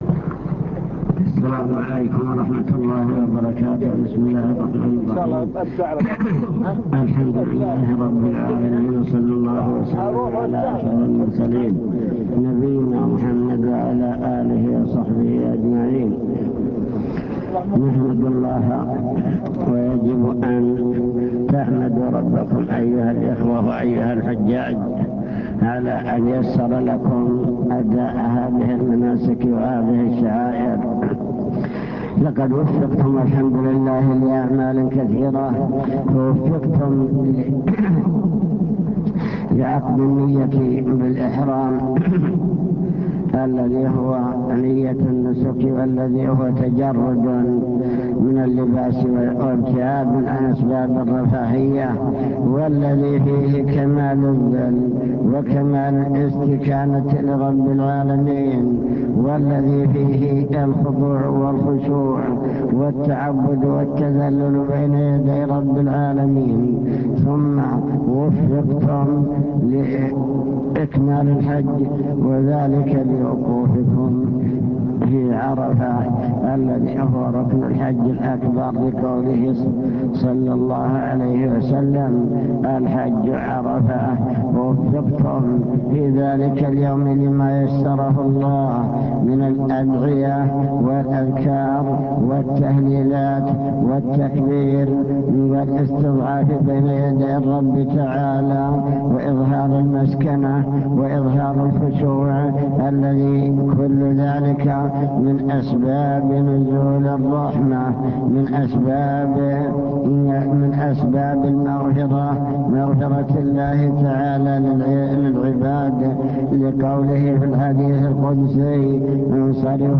المكتبة الصوتية  تسجيلات - محاضرات ودروس  أسئلة وكلمات حول الحج والذكر